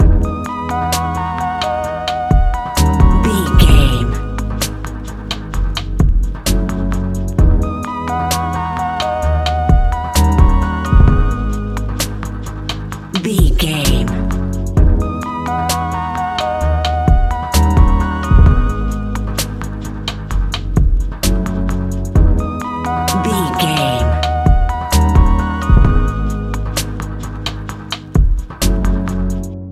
Ionian/Major
C♯
chill out
laid back
Lounge
sparse
new age
chilled electronica
ambient
atmospheric